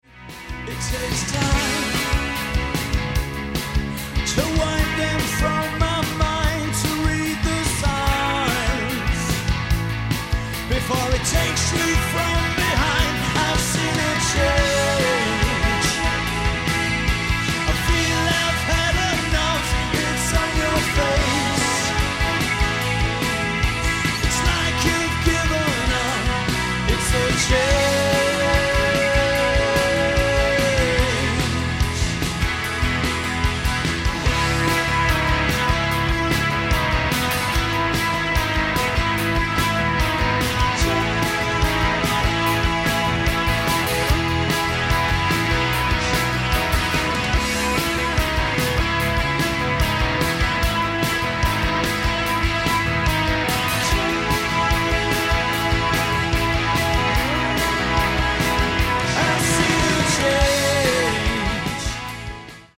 guitar and vocals.
power pop four piece
guitars
drums
bass